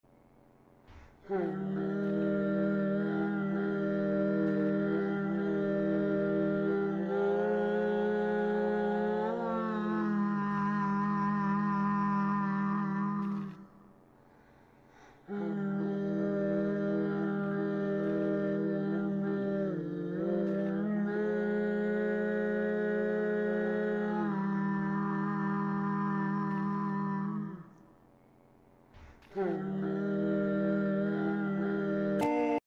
Screemu but he sounds slower and more chilling.